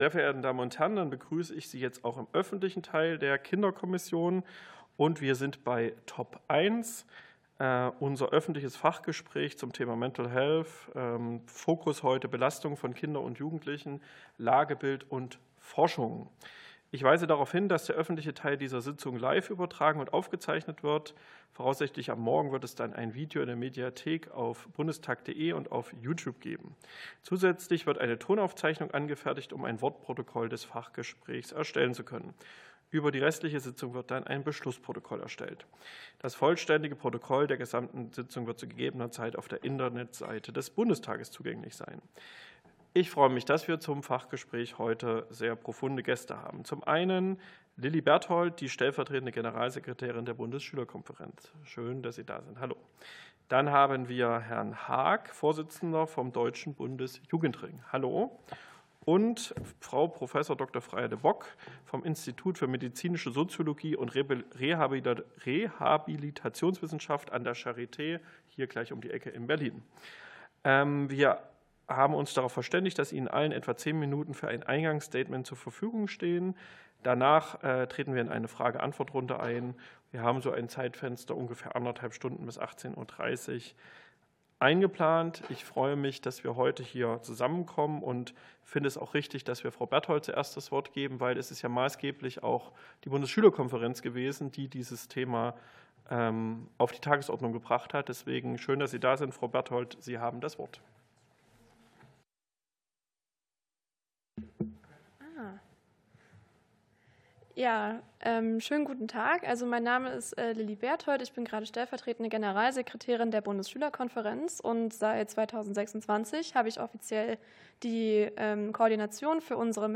Fachgespräch der Kinderkommission